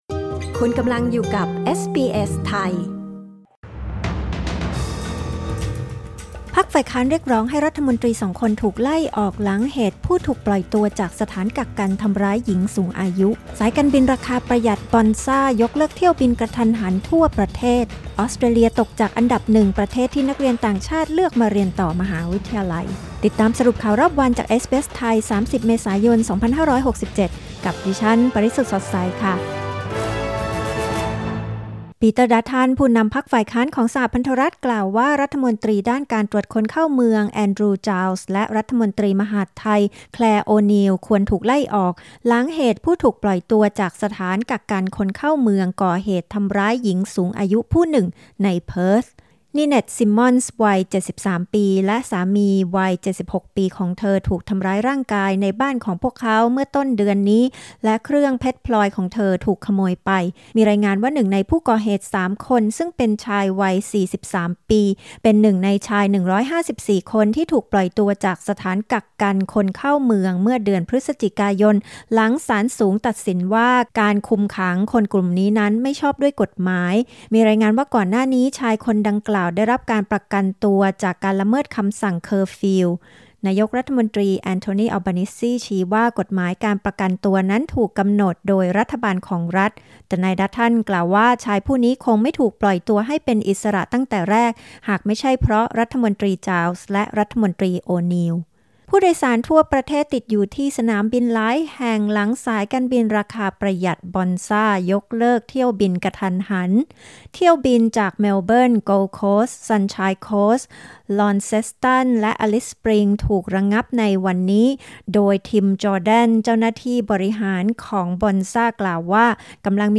สรุปข่าวรอบวัน 30 เมษายน 2567